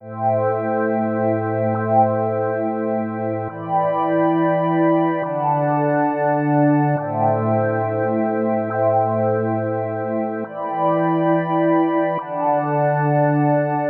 You can hear how the equalizer sweeps through the frequencies and create some interesting effect.
eq effect .mp3